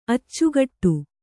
♪ accugaṭṭu